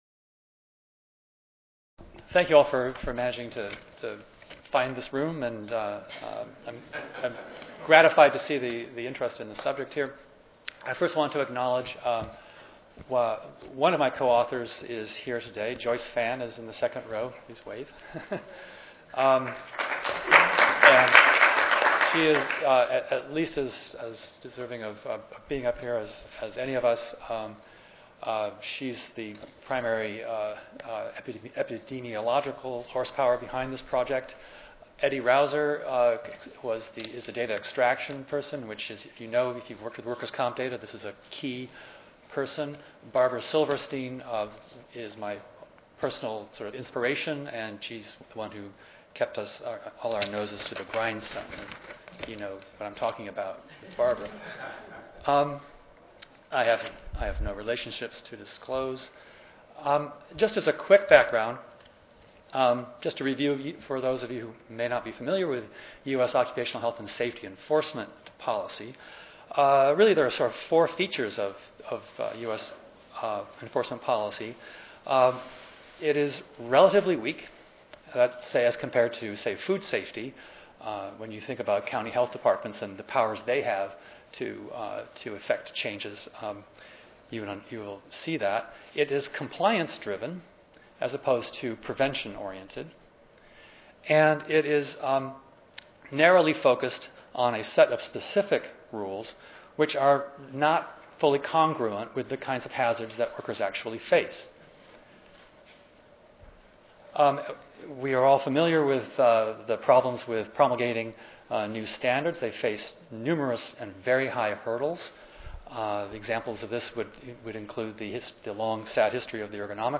4337.0 Scientific Award Lecture